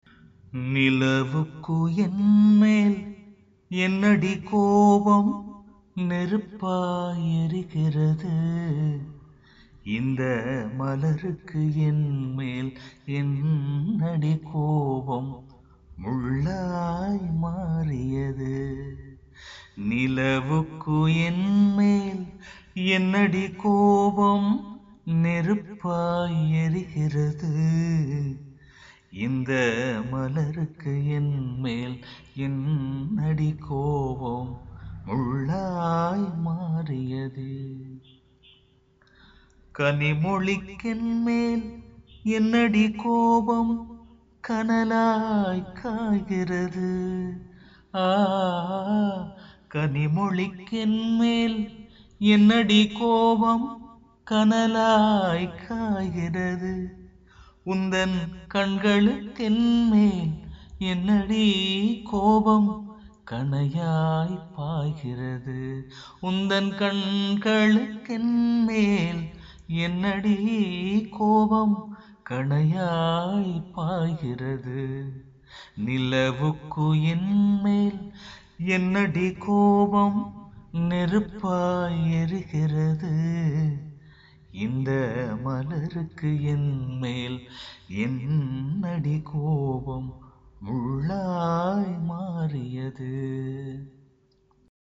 கடின மனதையும் கரைக்கும் ஒரு குரலுக்கு சொந்தக்காரர்....